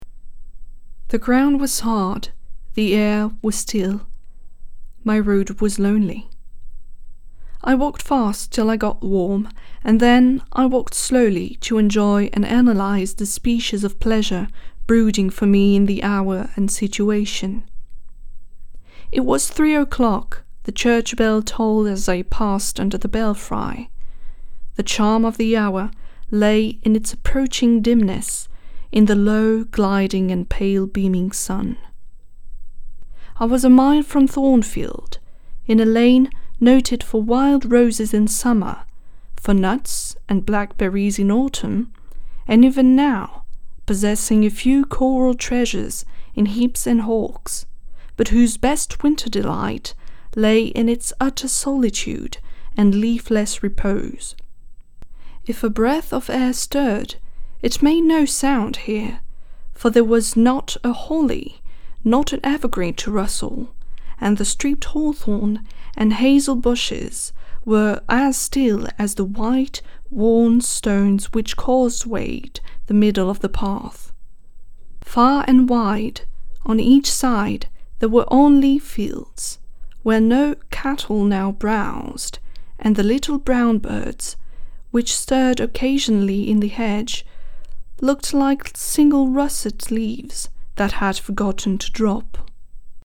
voice over demo RP English - extrait livre jane eyre
18 - 35 ans - Mezzo-soprano Soprano